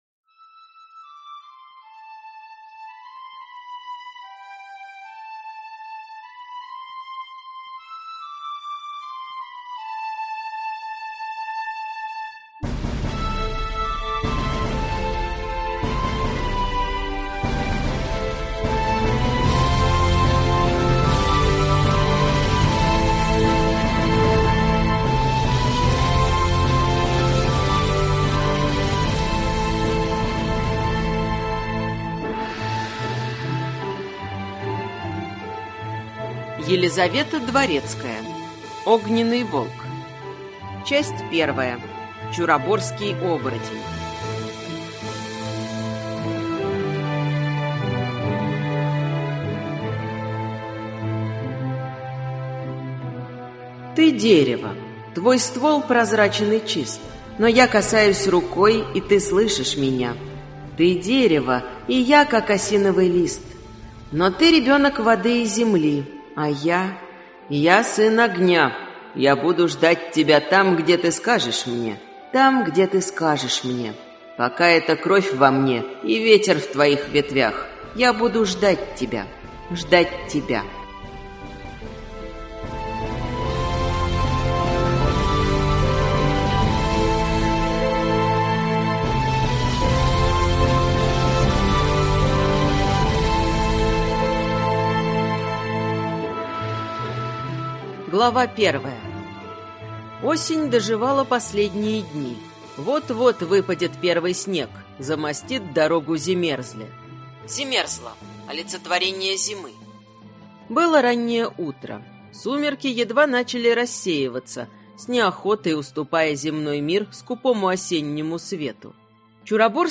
Аудиокнига Огненный волк. Книга 1: Чуроборский оборотень | Библиотека аудиокниг